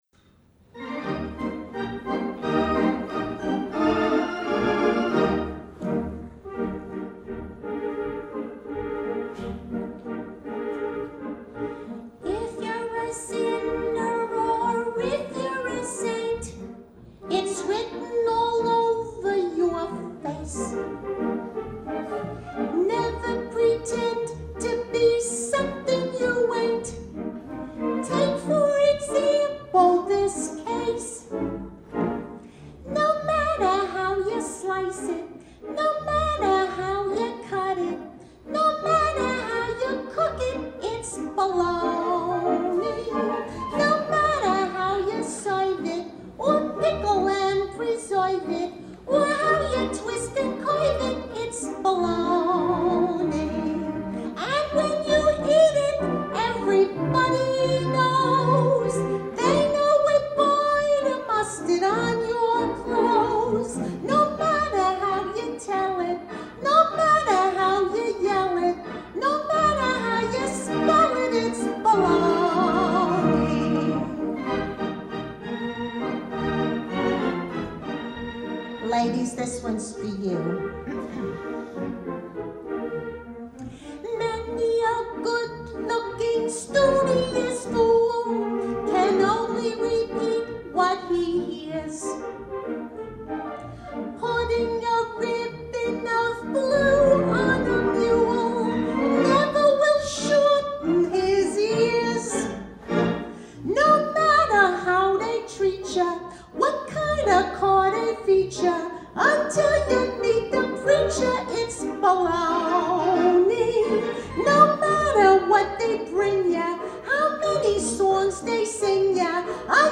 Recordings of the Capitol Wurlitzer